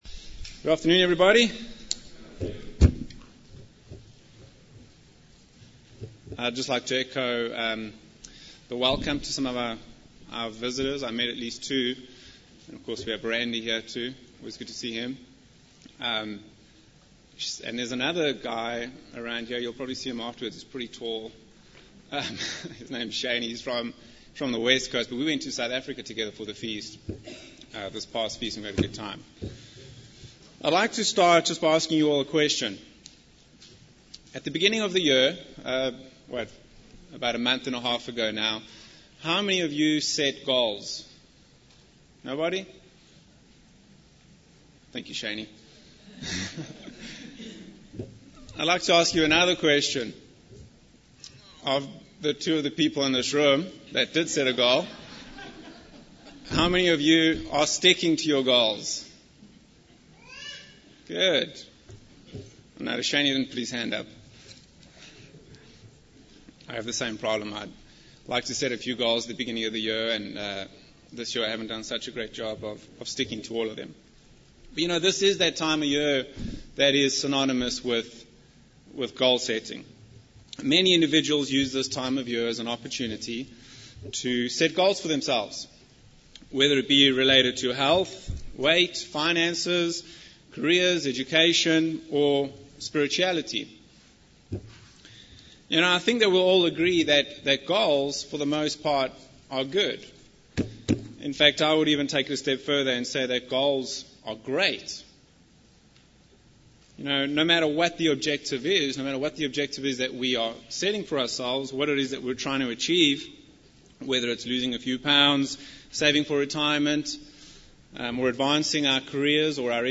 Our commander and Chief, Jesus Christ, has given us all a very specific goal - to seek first the Kingdom of God, and His righteousness. In this split sermon we will take a look at the road map that will help us achieve this goal.